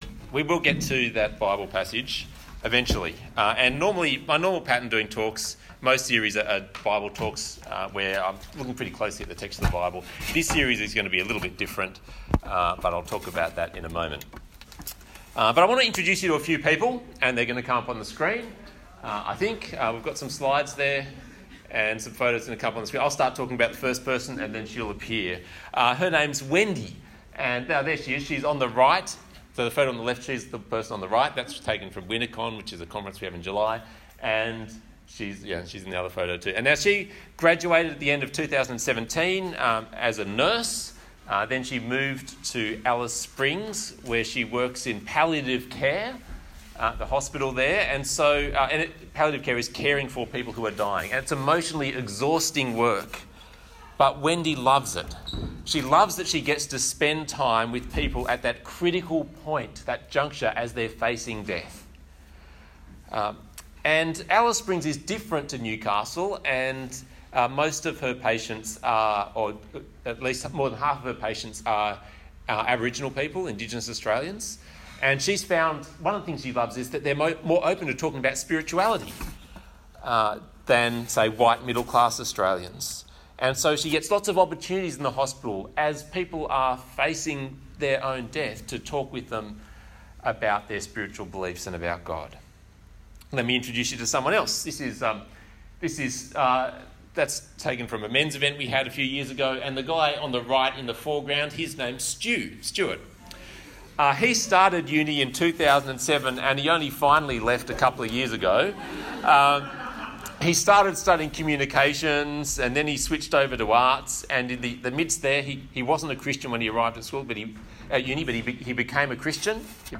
Talk Type: Bible Talk